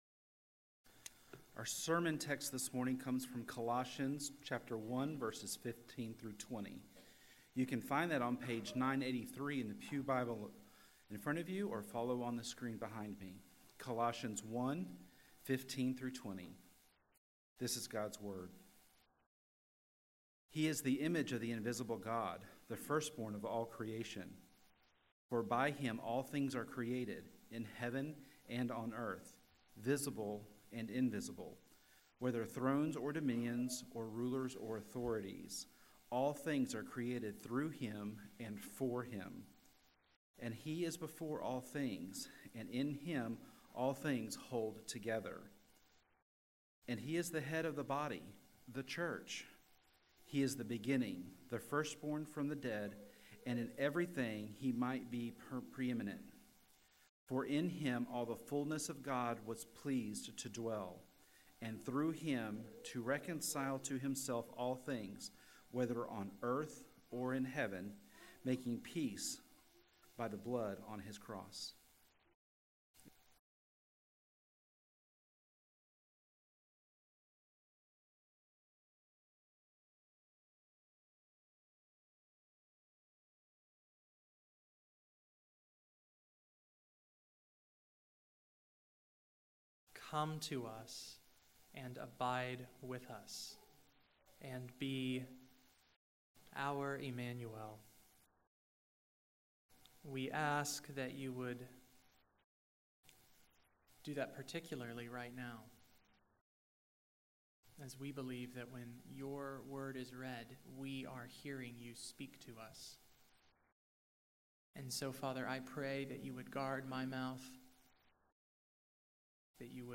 Morning Worship
There is a technical issue with the audio this week. It is recorded, but the quality is nor normal.